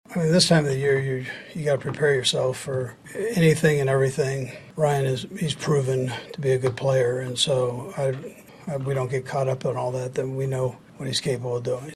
(cut) Chiefs head coach Andy Reid says that the team has to be prepared for Matt Ryan despite his slow start.